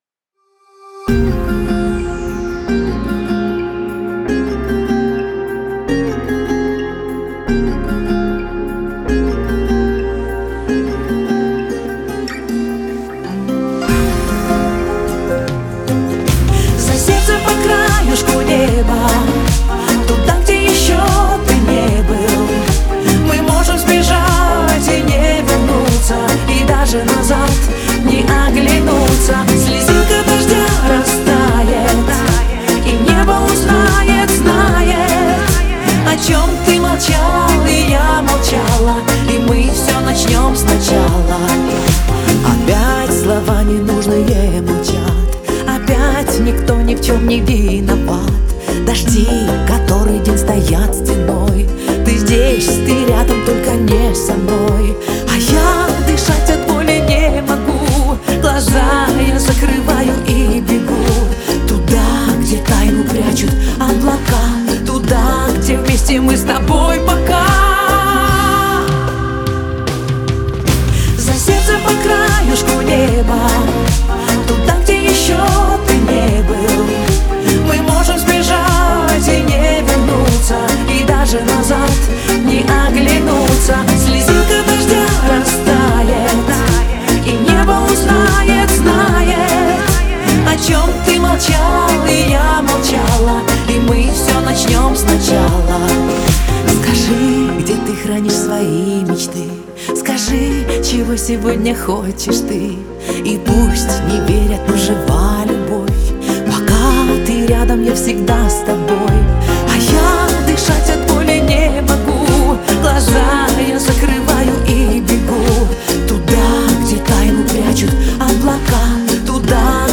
трогательная баллада